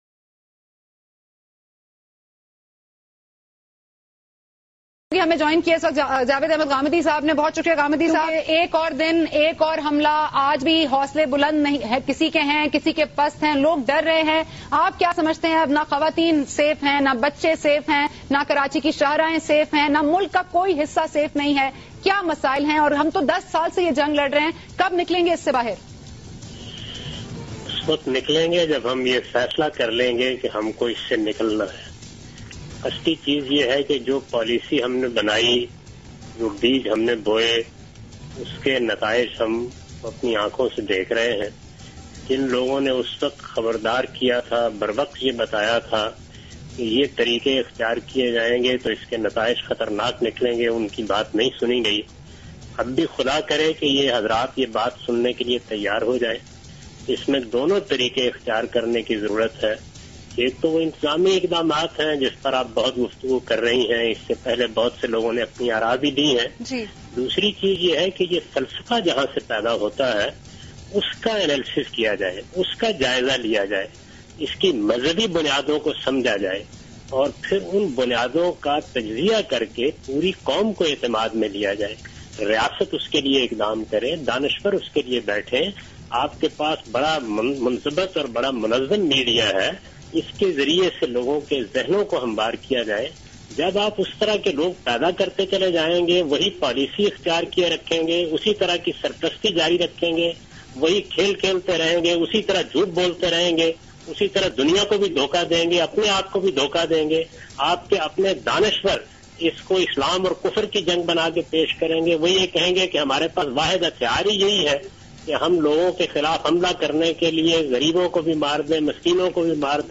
Category: TV Programs / Geo Tv / Questions_Answers /